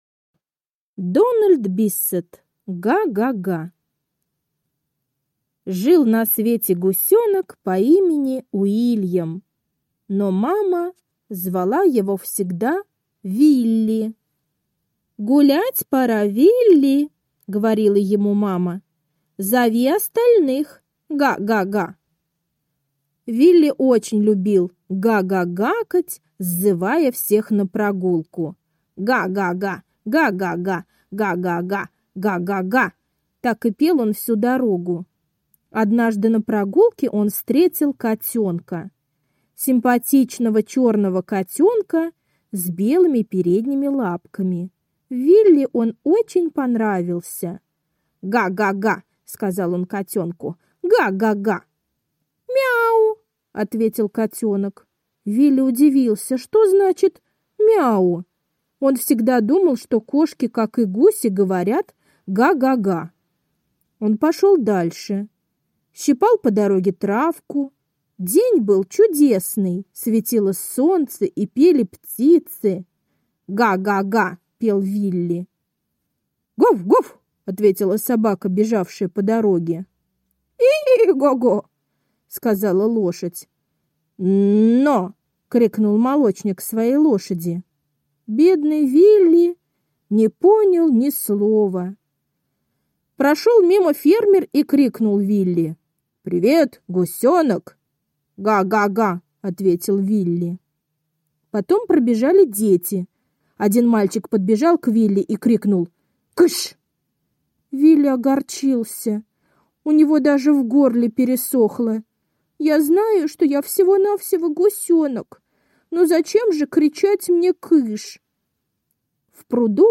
Га-га-га — аудиосказка Биссета Д. Однажды гусенок Вилли хотел с кем-нибудь поговорить, но все отвечали ему на непонятном языке…